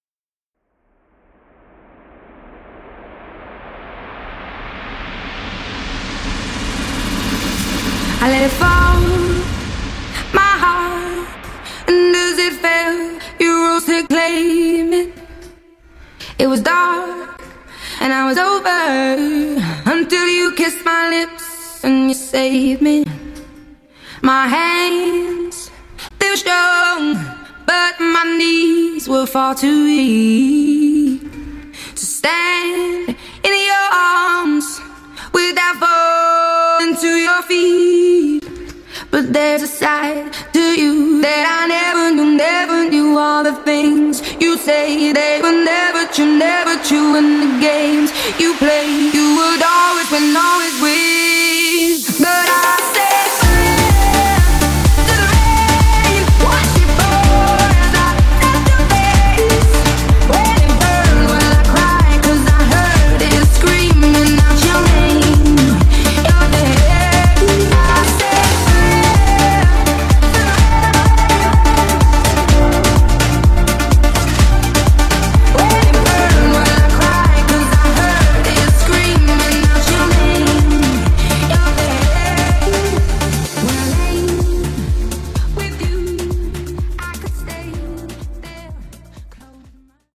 Acap Afro House)Date Added